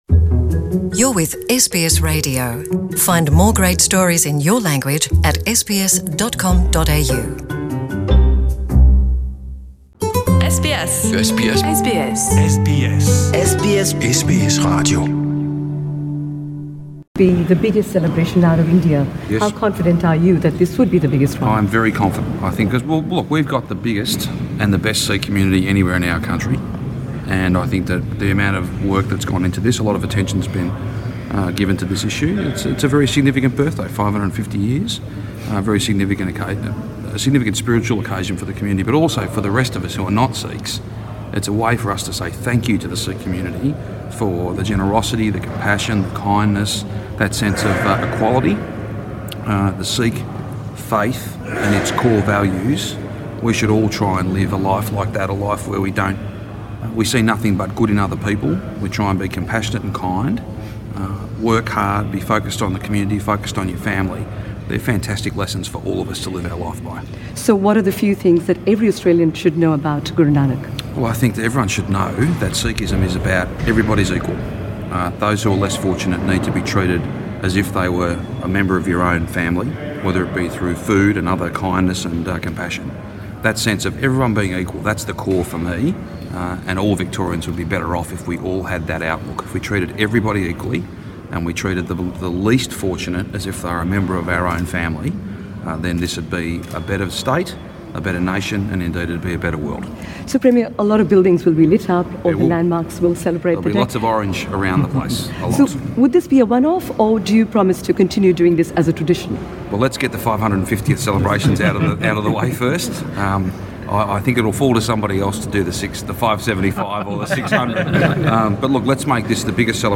Premier Daniel Andrews being interviewed by SBS Punjabi Source: SBS Punjabi